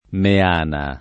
vai all'elenco alfabetico delle voci ingrandisci il carattere 100% rimpicciolisci il carattere stampa invia tramite posta elettronica codividi su Facebook Meana [ me # na ] top. — due comuni: M. di Susa (Piem.) e M. Sardo (Sard.)